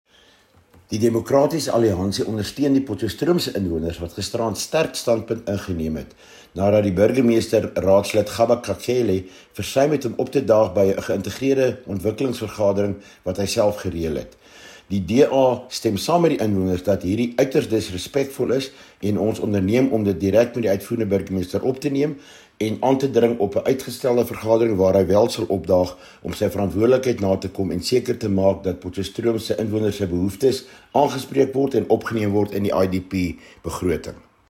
Afrikaans deur Rdl. Bertus le Roux.